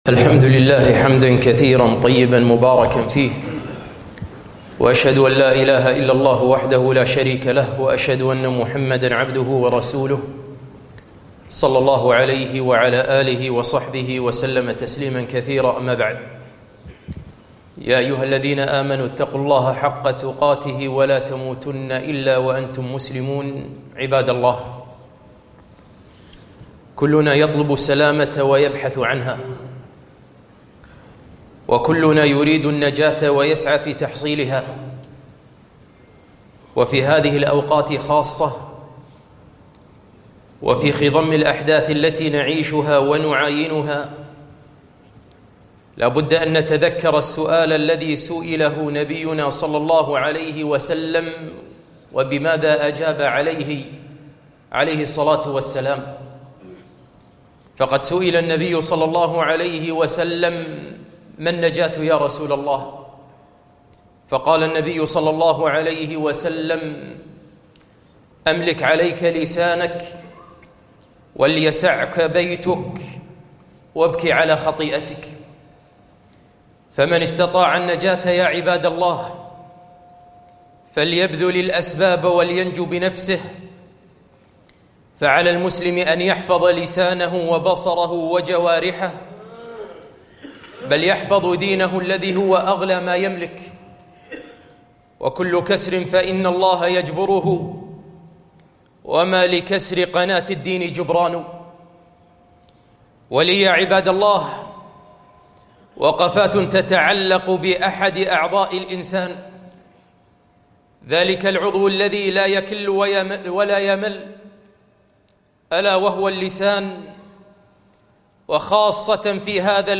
خطبة - احفظ لسانك زمن الانتخابات